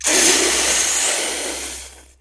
战斗的声音 " 靴子打到肚子上
描述：由衣架，家用餐具和其他奇怪物品制成的剑声。
Tag: 战斗 战争 弗利 战斗 行动